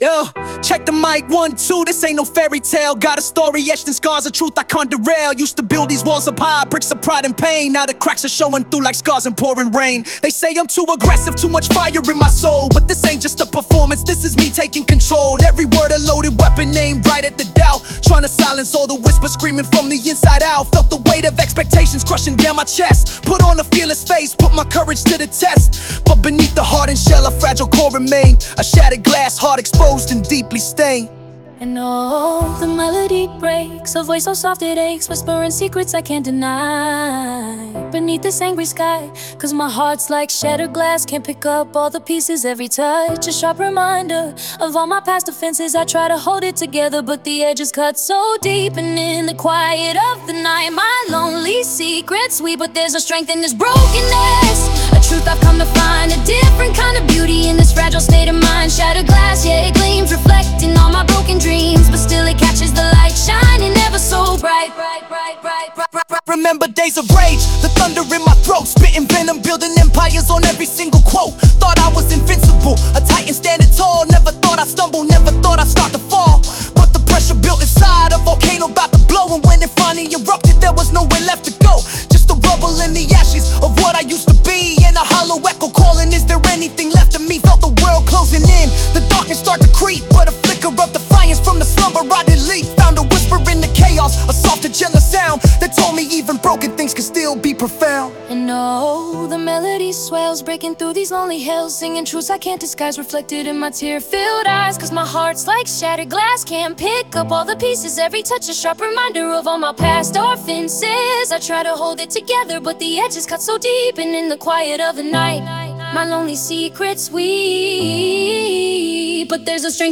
Eminem style rap with a Sia soft touch